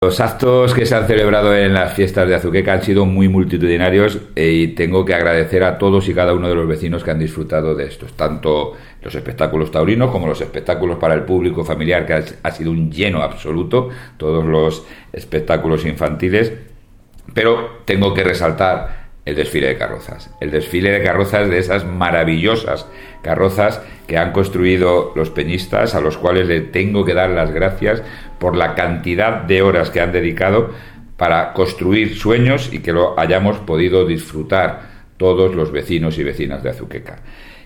Declaraciones del alcalde José Luis Blanco 2